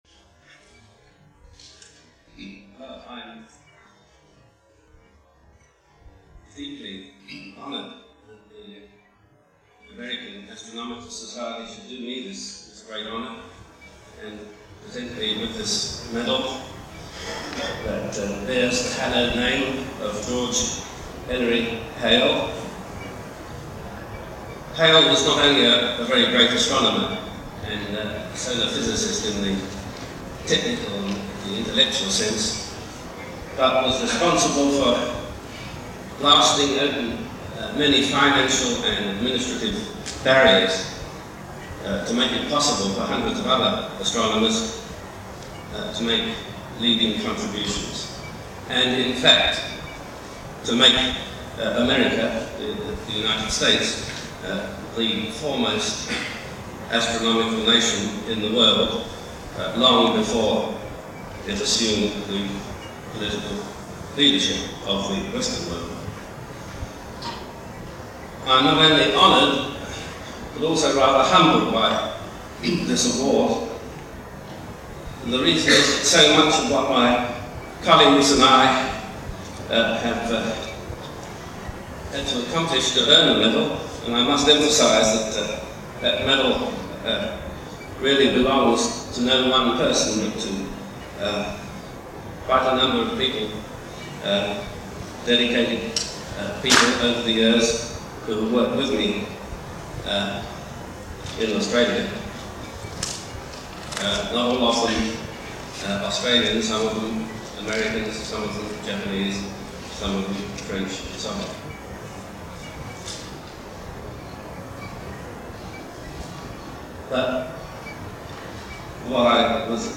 G.E. Hale Prize Lecture #156, American Astronomical Society, 17 June 1980, University of Maryland, College Park